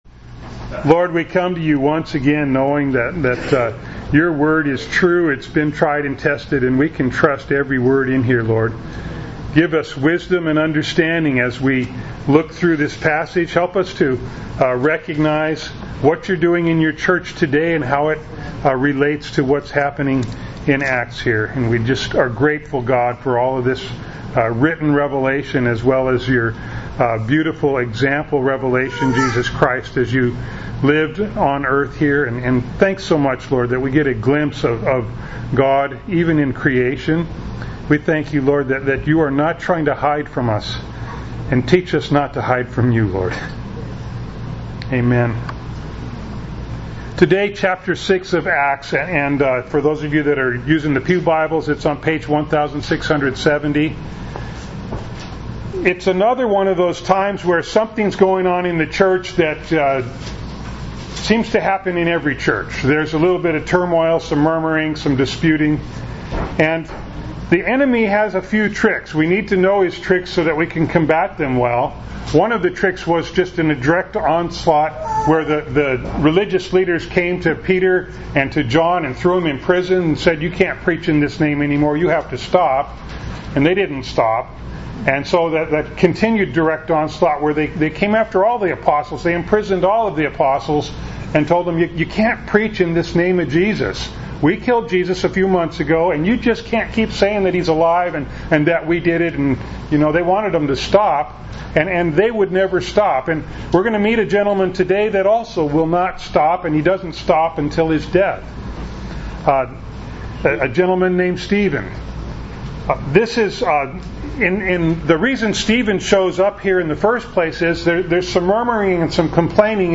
Acts 6:1-15 Service Type: Sunday Morning Bible Text